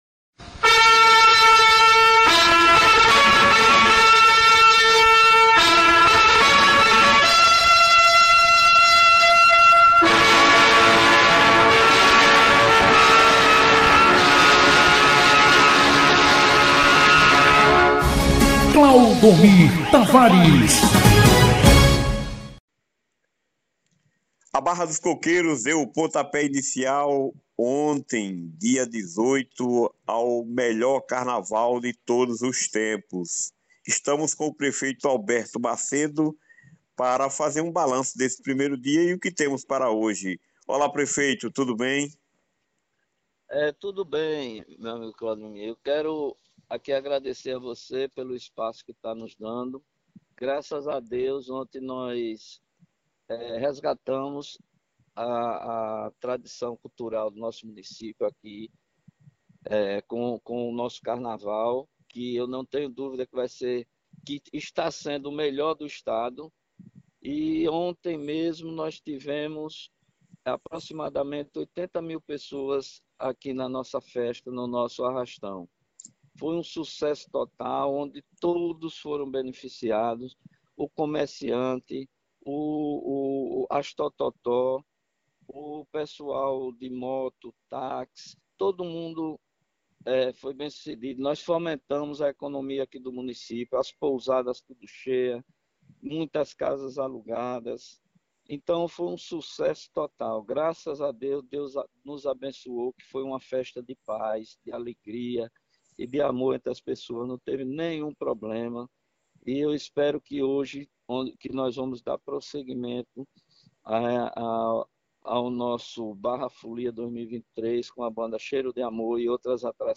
Áudio: Entrevista com o prefeito ALBERTO MACEDO, fazendo um balanço do primeiro dia do BARRA FOLIA, o carnaval de Barra dos Coqueiros
Confira na íntegra o áudio da entrevista disponibilizado pela emissora: